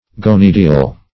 gonydial - definition of gonydial - synonyms, pronunciation, spelling from Free Dictionary Search Result for " gonydial" : The Collaborative International Dictionary of English v.0.48: Gonydial \Go*nyd"i*al\, a. (Zool.) Pertaining to the gonys of a bird's beak.